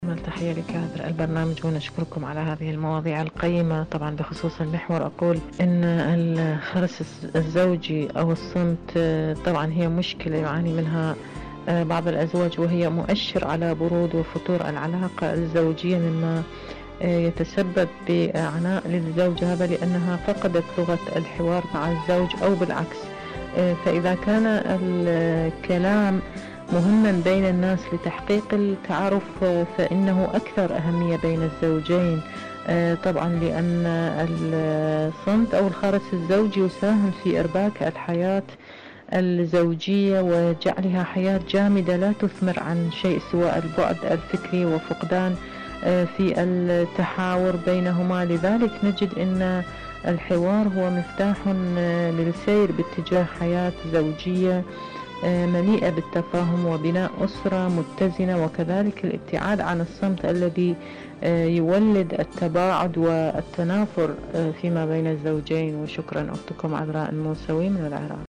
مشاركة صوتية